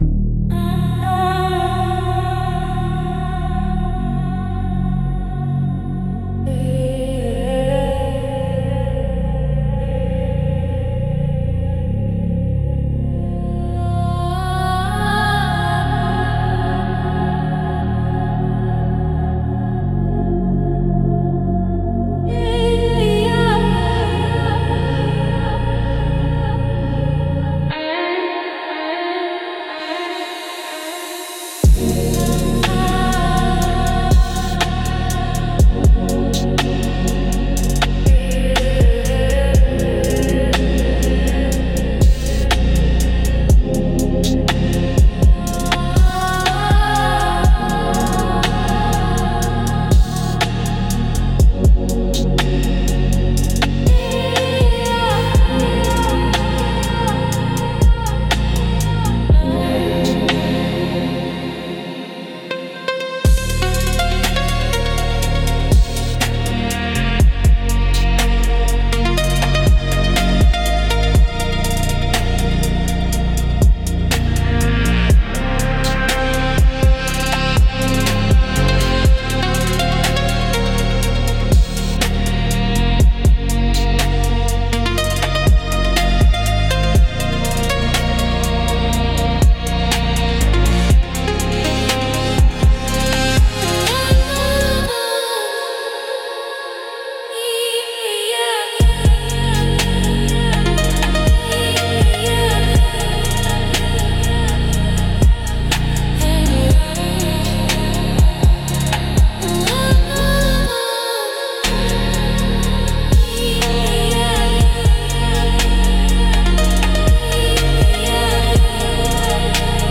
Instrumental - Melancholy of the 808 - 3.44